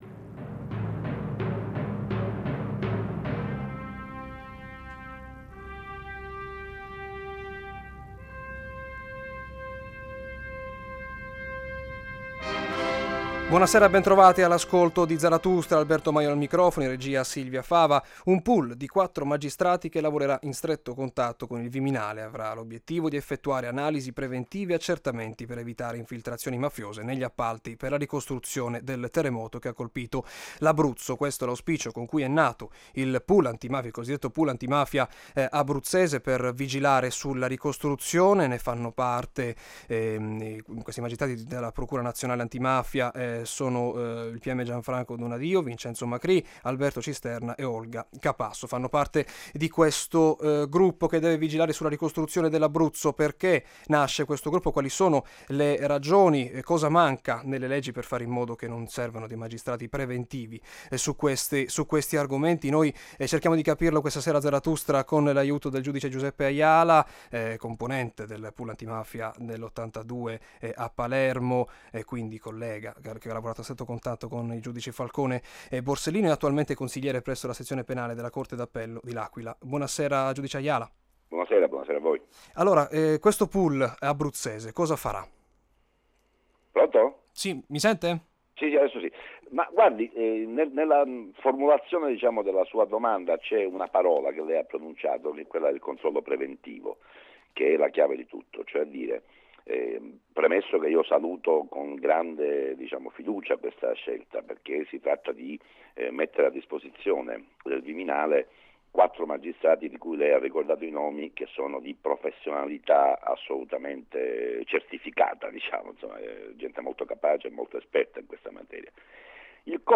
Ne abbiamo parlato con Giuseppe Ayala, protagonista insieme a Giovanni Falcone e Paolo Borsellino della stagione del pool antimafia siciliano e autore di "Chi ha paura muore ogni giorno".
Ascolta la puntata di Zarathustra andata in onda sabato 9 maggio, alle 18,05, su Radio Italia anni '60 - Emilia Romagna.